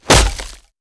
砍树－YS070511.wav
通用动作/01人物/06工作生产/砍树－YS070511.wav